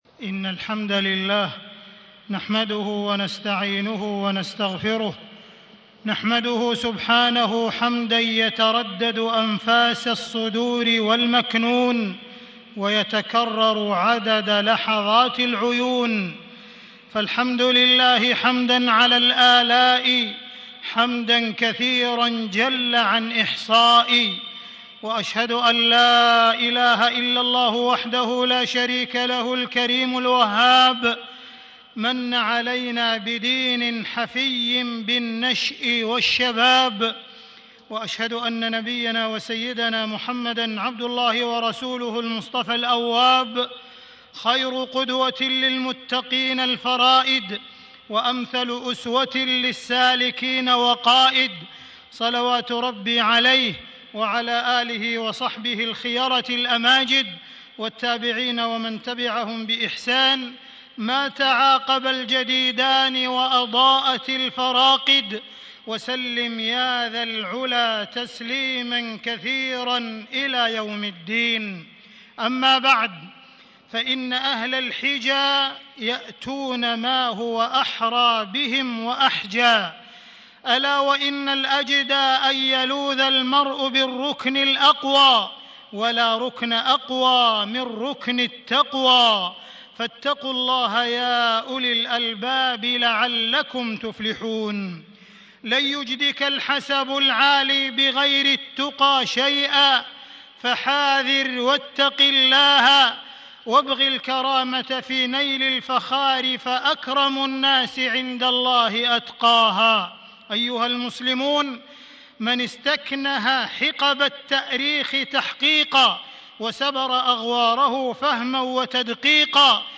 تاريخ النشر ١٥ شوال ١٤٣٦ هـ المكان: المسجد الحرام الشيخ: معالي الشيخ أ.د. عبدالرحمن بن عبدالعزيز السديس معالي الشيخ أ.د. عبدالرحمن بن عبدالعزيز السديس الشباب وأزمة الفكر والسلوك